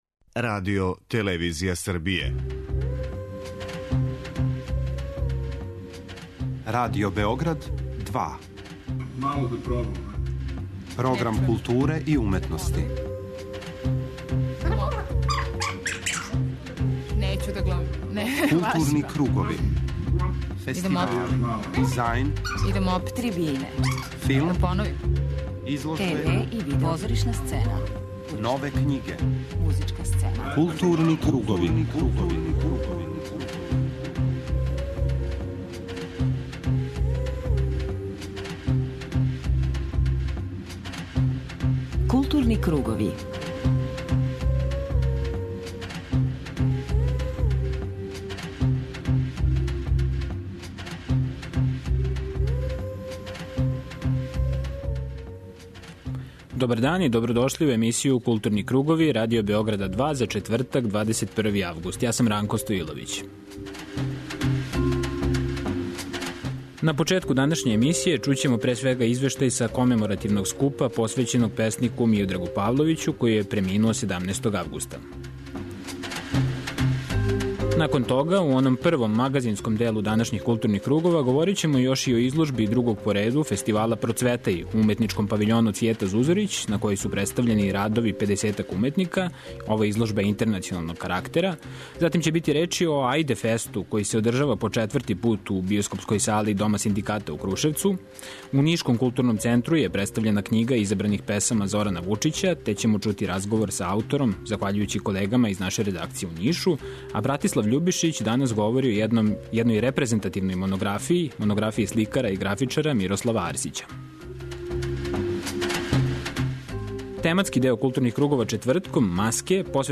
У првом делу емисије информисаћемо вас о актуелним културним догађајима. У тематском блоку 'Маске' посвећеном позоришту слушаћете два разговора емитована током позоришне сезоне за нама.